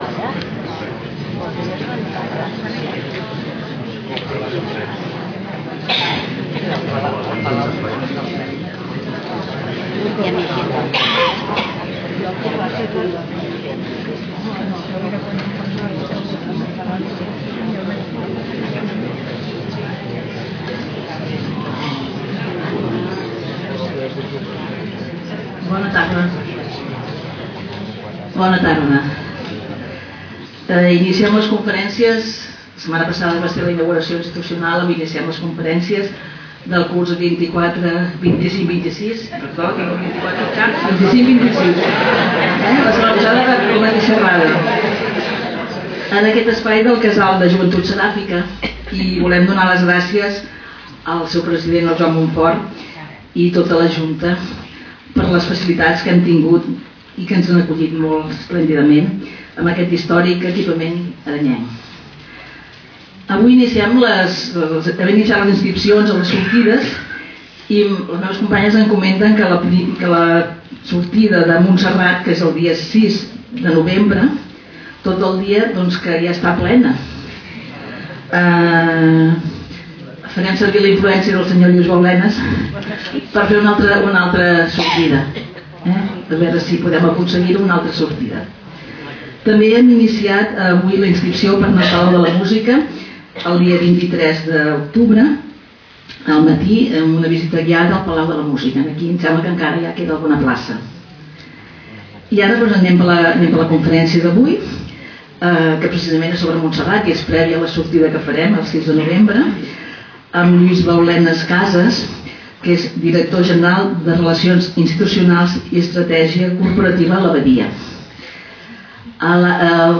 Lloc: Casal de Joventut Seràfica Audio
Categoria: Conferències